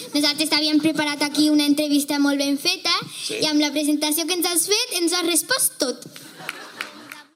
Fragment de l'emissió inaugural en FM com a ràdio municipal en la qual van participar els alumnes de l'Escola Alfred Potrony.